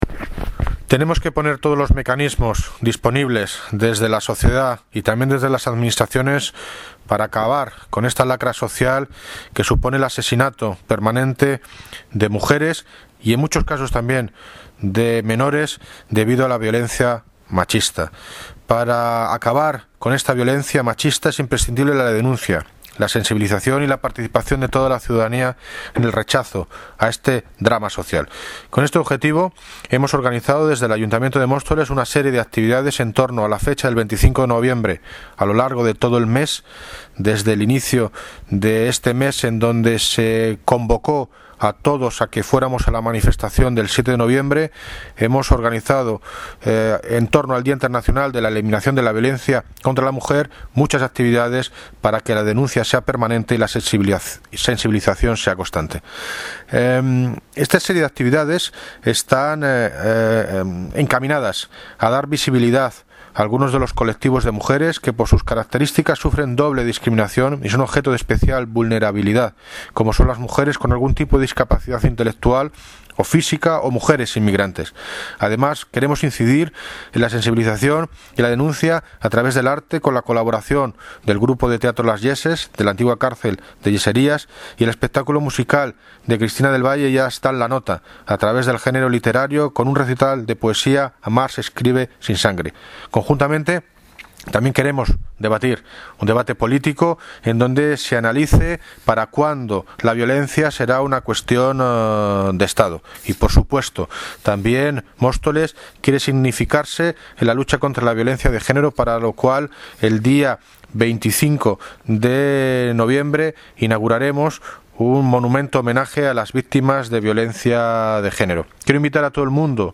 Audio - David Lucas (Alcalde de Móstoles) Sobre Actividades contra la violencia de género